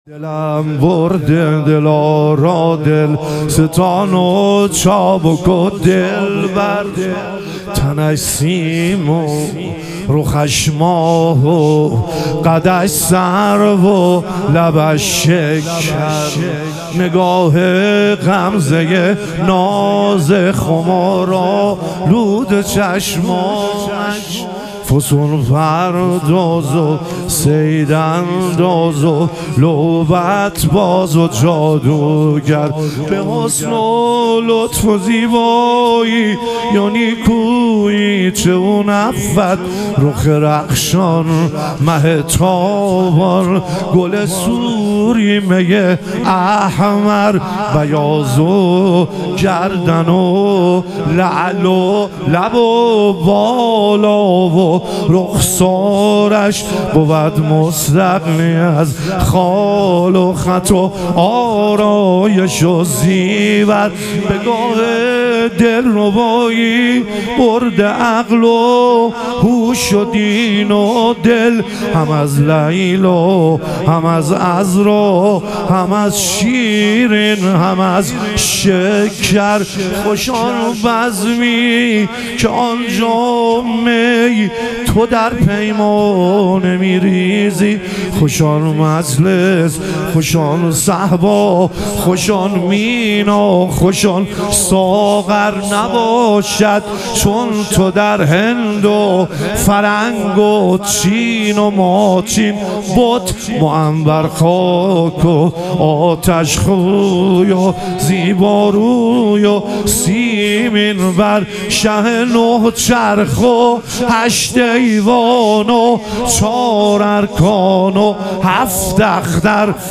ظهور وجود مقدس حضرت علی اکبر علیه السلام - مدح و رجز